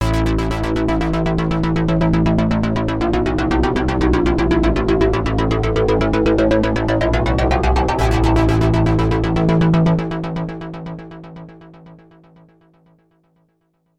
Novation Peak – Klangbeispiele
novation_peak_test__esq1.mp3